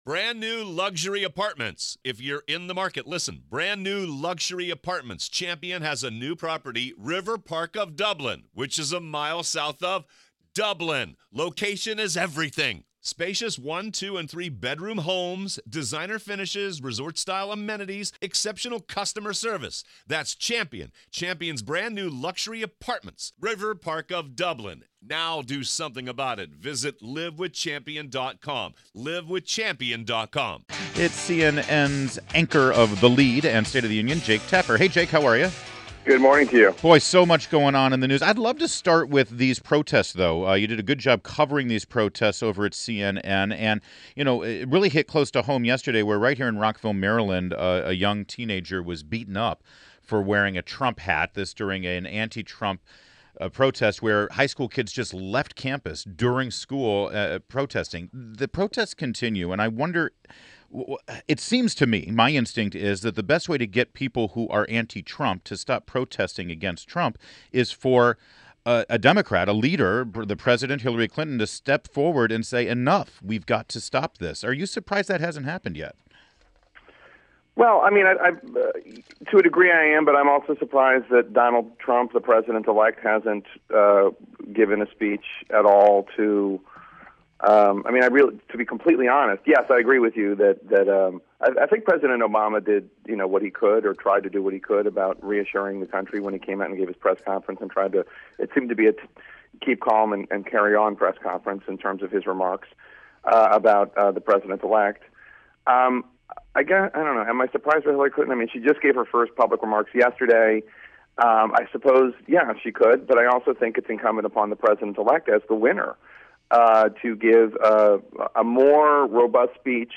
INTERVIEW — JAKE TAPPER – Anchor of CNN’s THE LEAD and STATE OF THE UNION – discussed the nationwide anti-Trump protests.